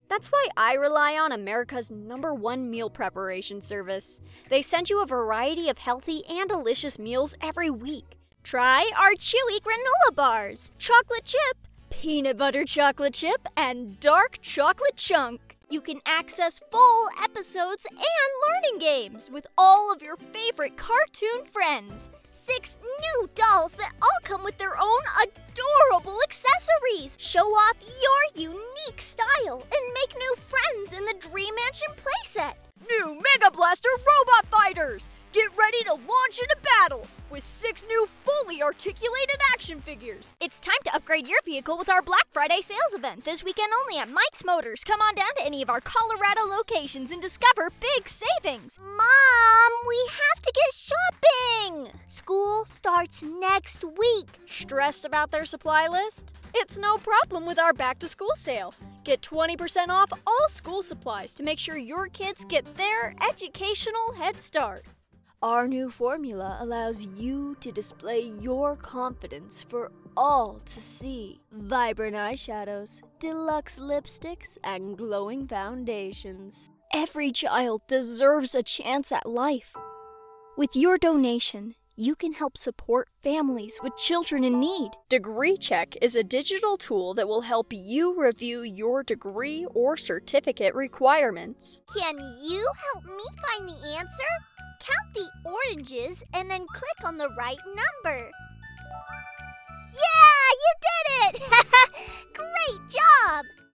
Young Female Voice Acting Talent
Voice Samples
sample-commercial-reel.mp3